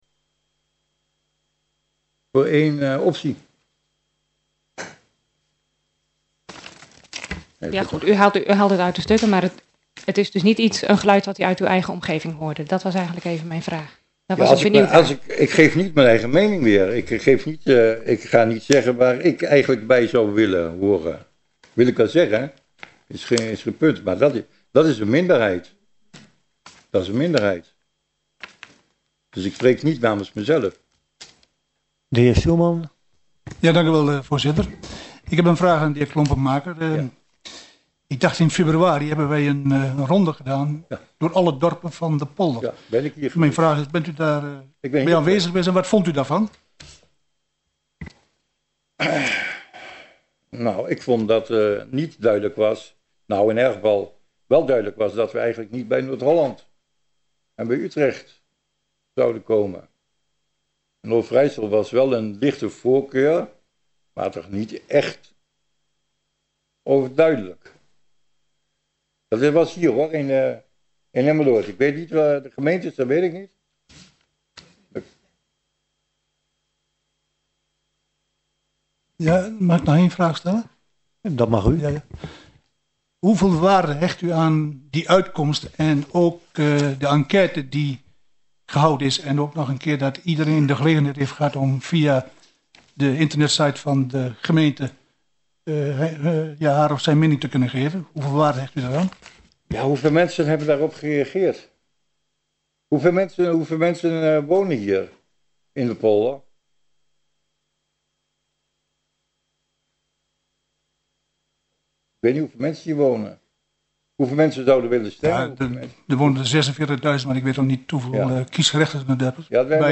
Locatie: Raadzaal
Op de opnameband mist naar schatting zo'n 4 minuten (inclusief opening / mededelingen / vaststelling agenda).